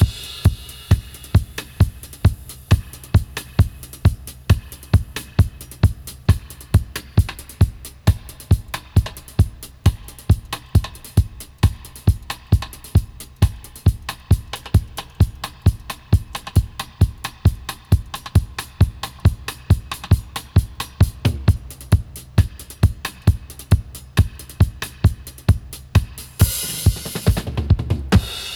134-FX-03.wav